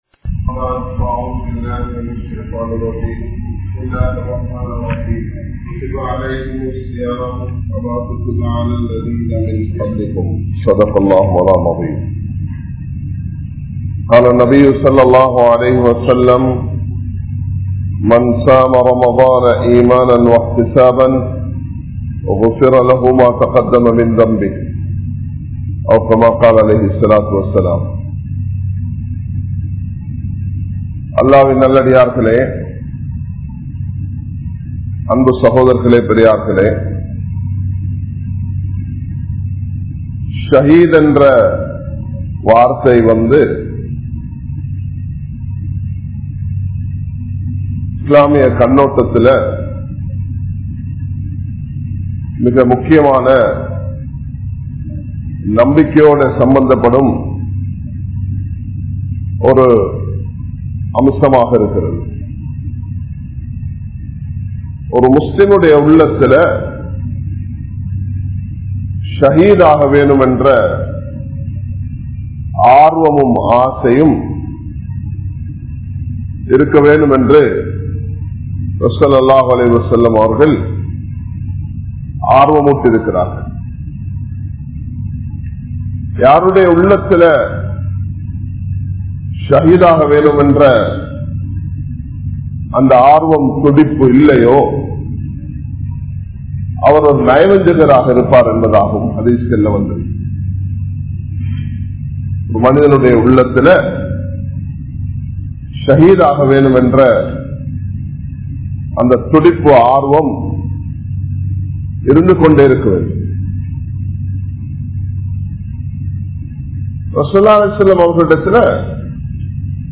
Shaheedh Entraal Yaar? | Audio Bayans | All Ceylon Muslim Youth Community | Addalaichenai
Kollupitty Jumua Masjith